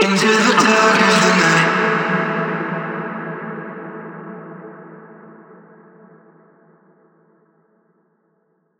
VR_vox_hit_intothedark_low_E.wav